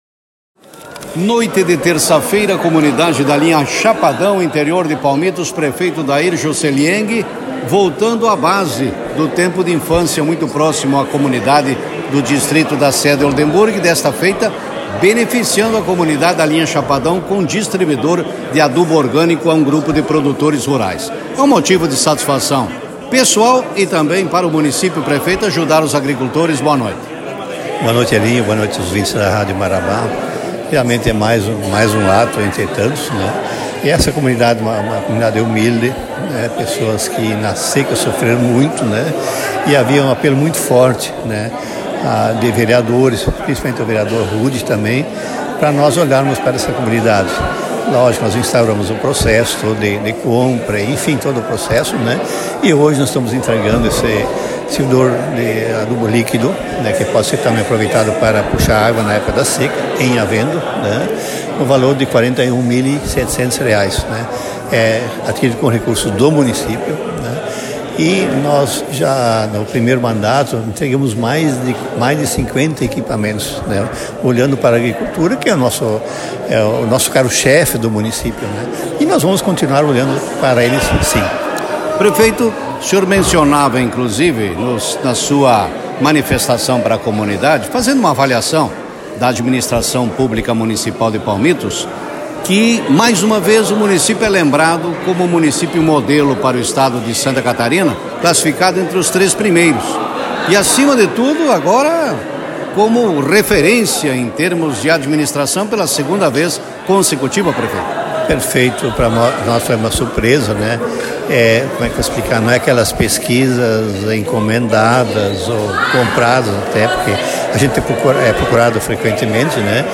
Manchete